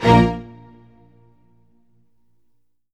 ORCHHIT A01R.wav